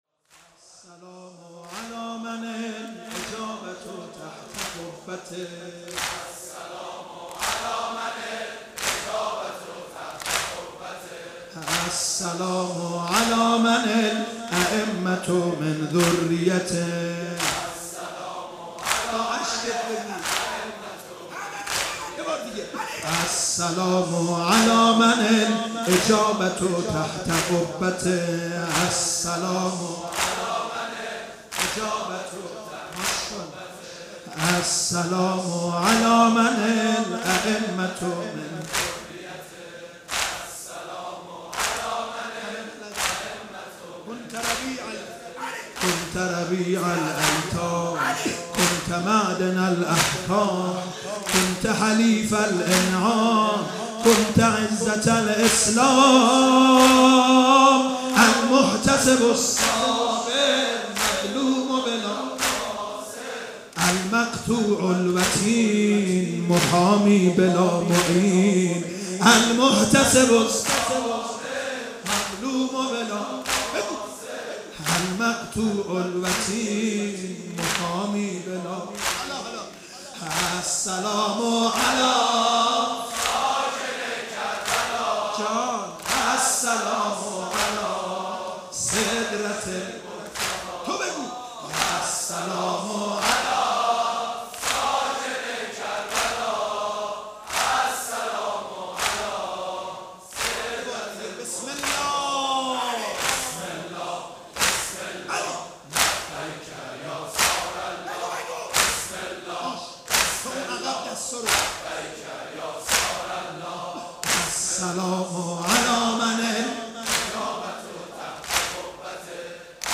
شب پنجم محرم 96 - پیش زمینه - السلام علی من الاجابه تحت قبه